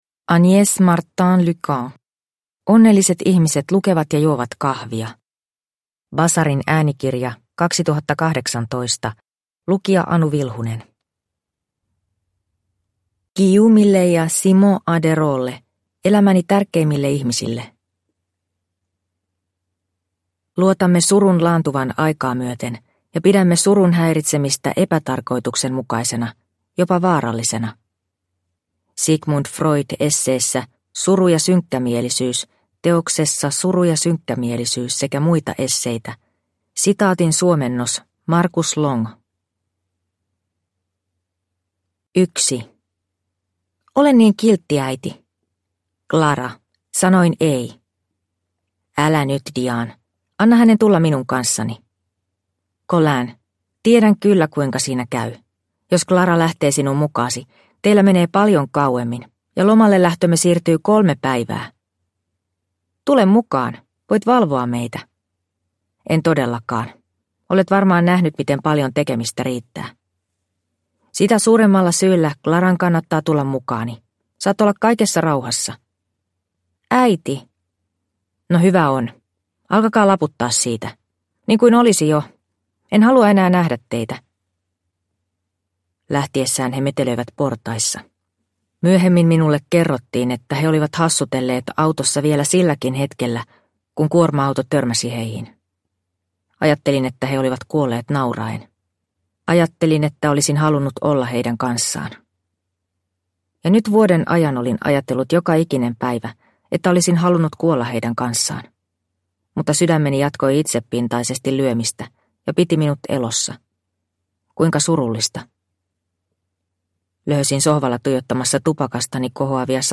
Onnelliset ihmiset lukevat ja juovat kahvia – Ljudbok – Laddas ner